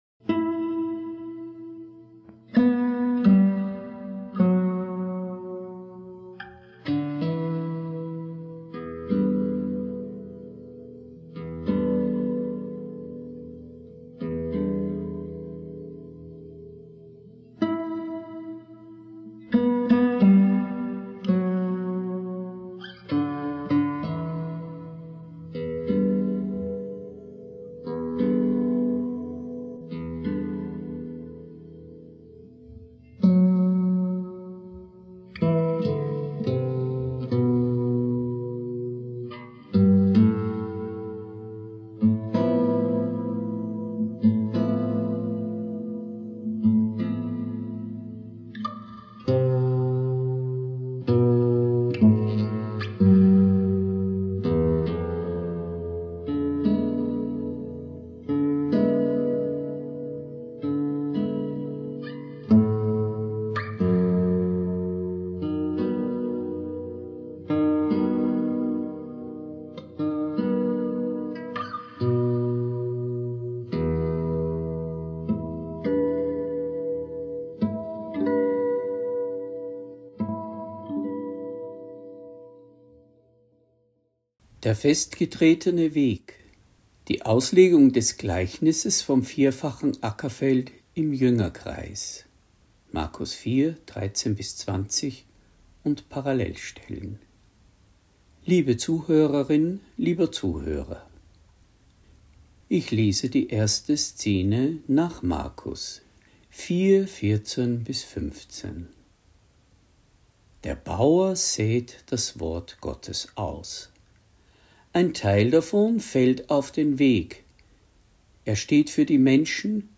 Predigt | NT02 Markus 4,13-20 Das 4-fache Ackerfeld (2) Festgetretener Weg – Glauben und Leben